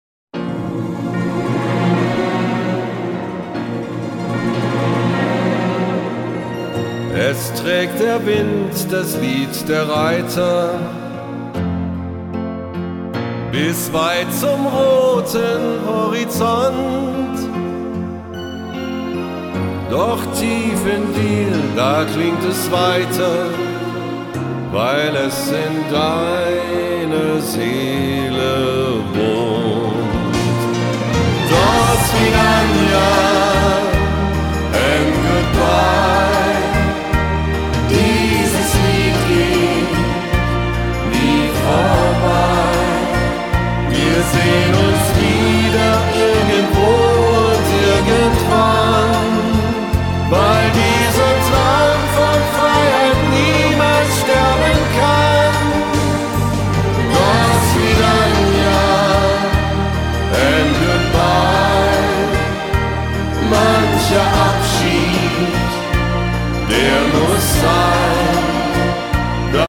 Disco-Pop